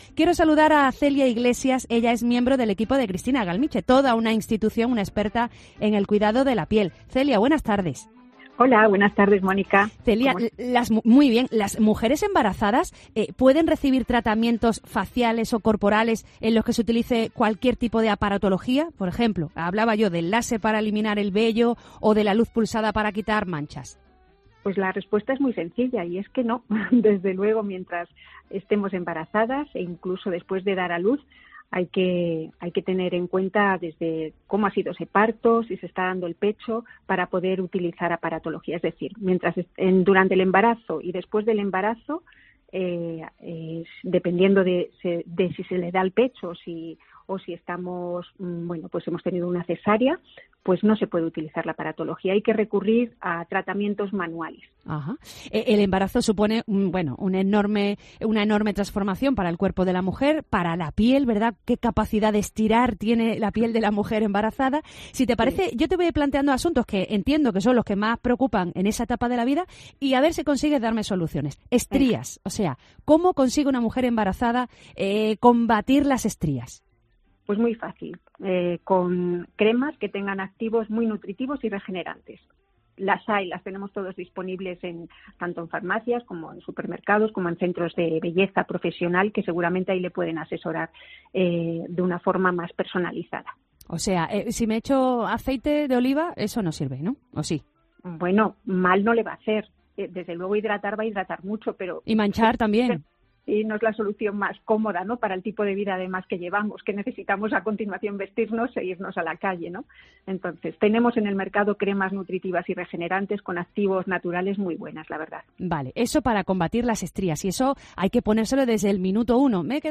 Escucha los consejos de una experta para cuidar tu piel de la mejor manera durante el embarazo